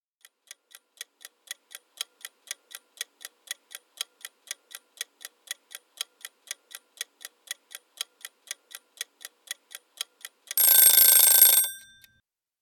Kitchen Timer — Done!
bell ding field-recording kitchen timer sound effect free sound royalty free Sound Effects